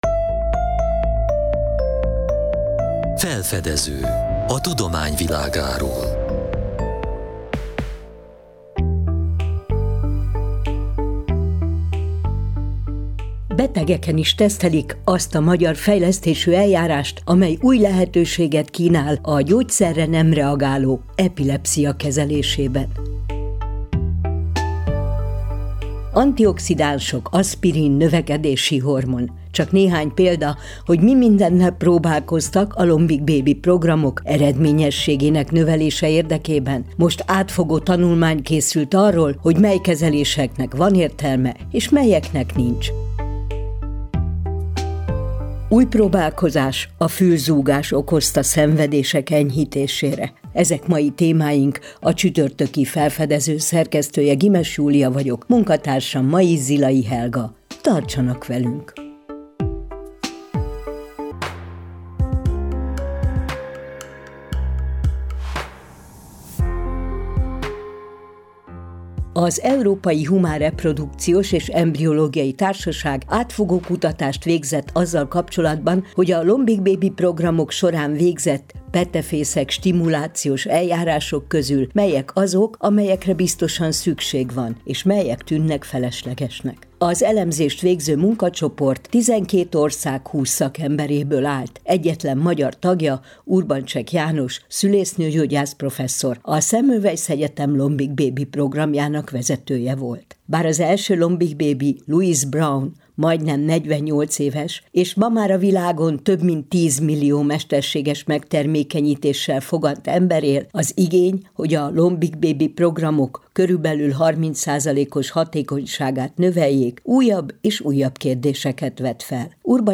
a Kossuth Rádió „Felfedező” c. műsorában az asszisztált reprodukció aktuális kérdéséről, a petefészek stimulációjával kapcsolatos új irányelvről beszélgetett.